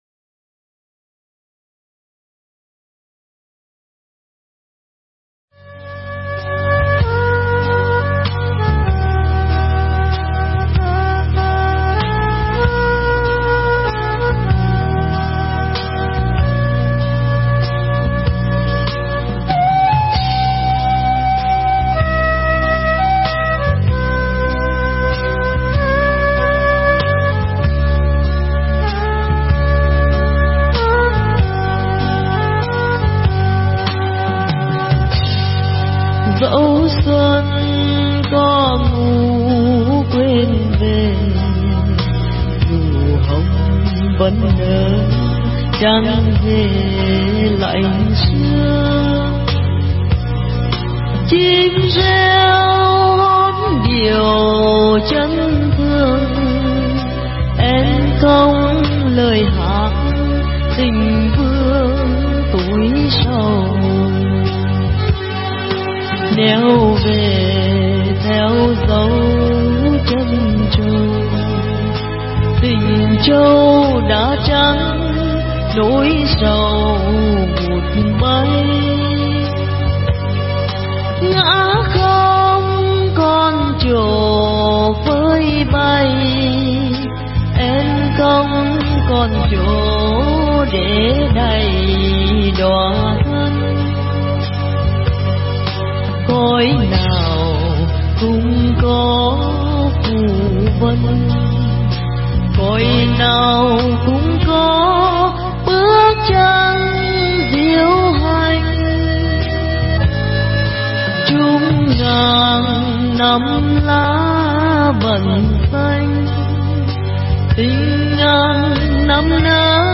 Pháp thoại
giảng tại chùa Phước Duyên (Huế) ngày mùng 2 Tết Xuân Đinh Hợi (2007)